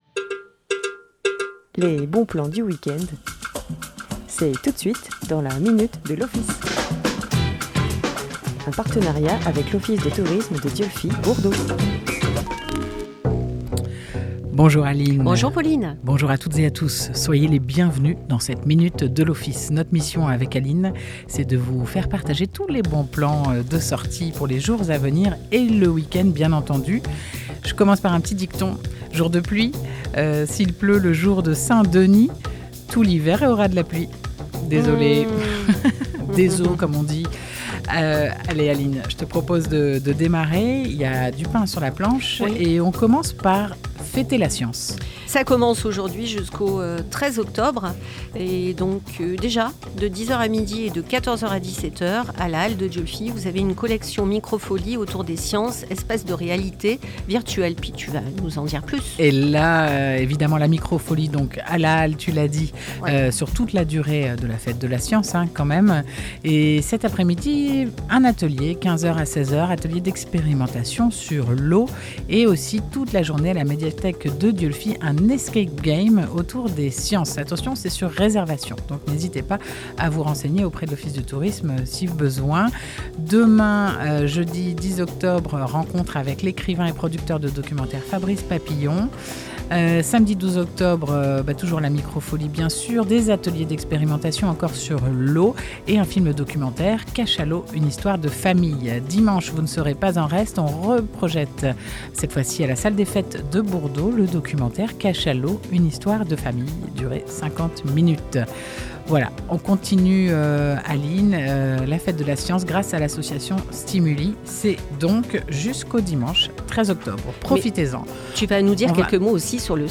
Chaque mercredi à 9h30 en direct